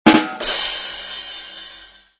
Rim Shot